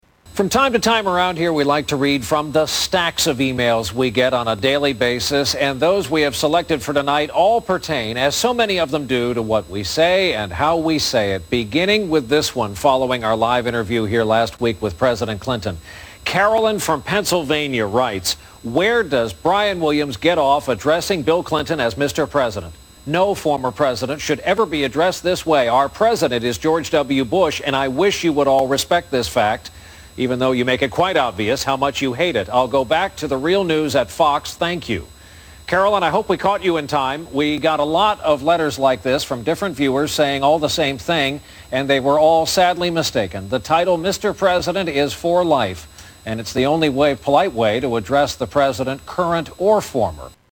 Tags: Media News Brian Williams News Anchor NBC Nightly News